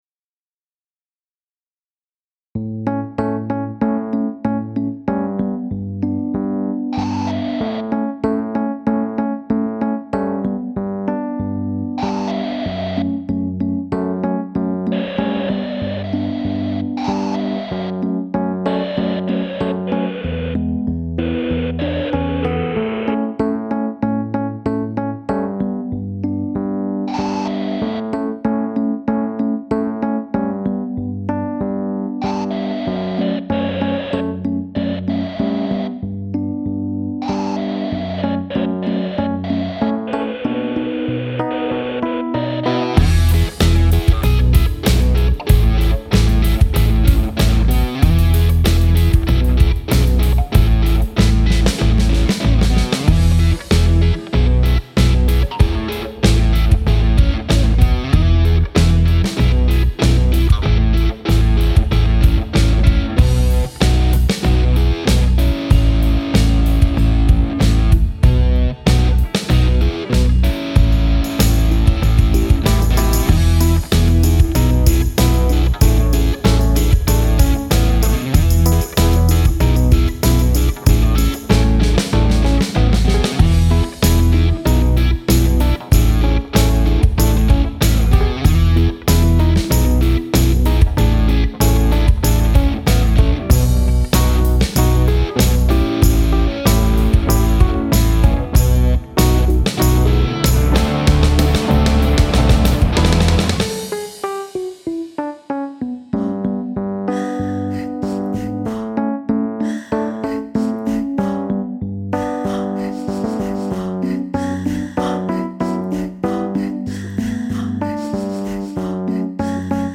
I set this song up with a ridiculously slow BPM: 95.
Not being a drummer I like to use loops and at 95 bpm, all my loops sounded lethargic. So I had to edit drums a lot.